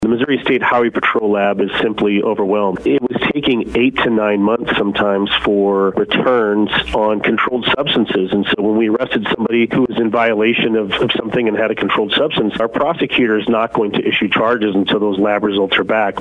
Jefferson County Sheriff, Dave Marshak, says it's being added to the county's bulk evidence buidling that's already located at the site.